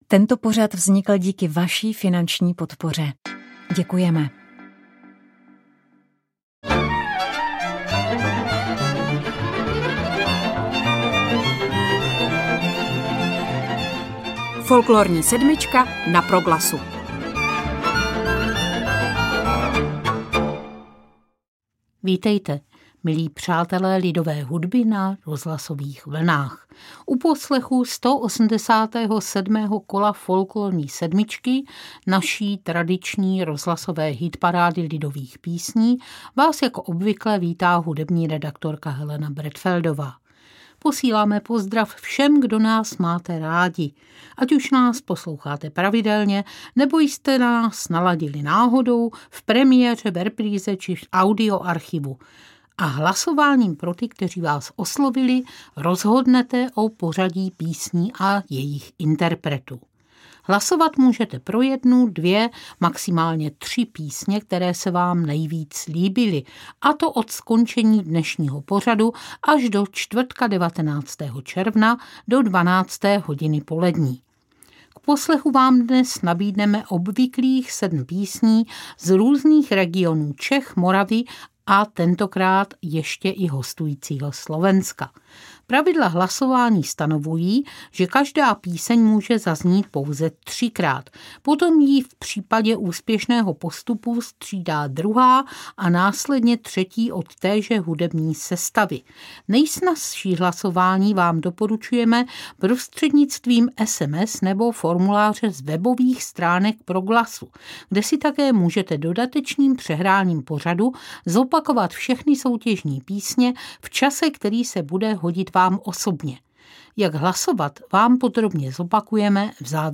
Tradiční hitparáda lidových písní
sedm lidových písní z Moravy i z Čech
valčíkem
tesknou baladou s tragickým koncem
rodinné cimbálové muziky